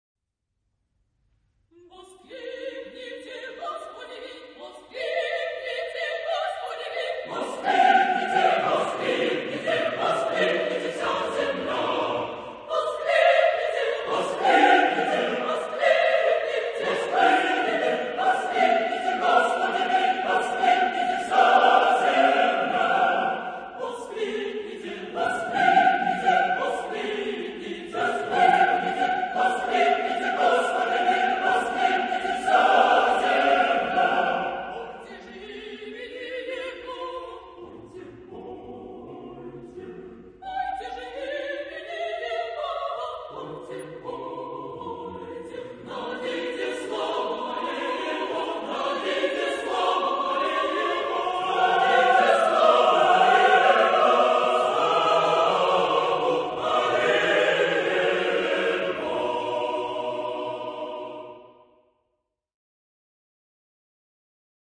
SATB (4 voices mixed).
Type of Choir: SATB (4 mixed voices )